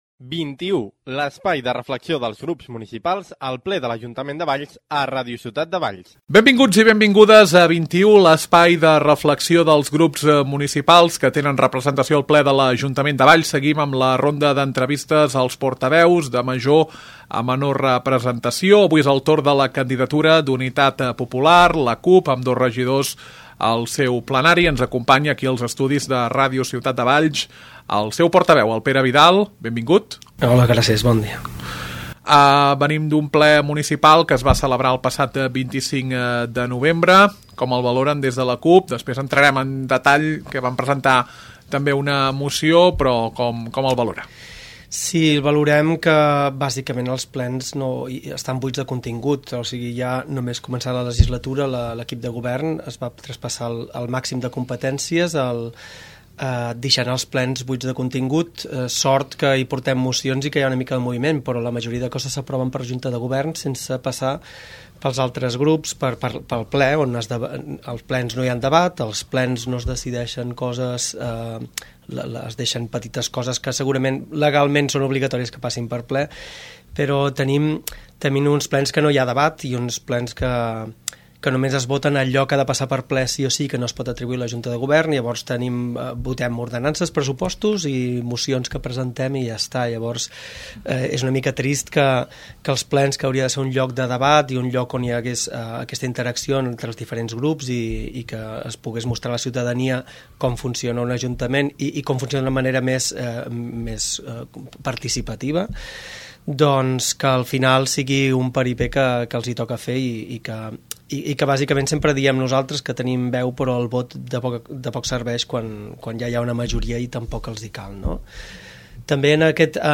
Ronda d’entrevistes als diferents portaveus de major a menor proporció a la sala de plens. Avui és el torn de Pere Vidal de la CUP.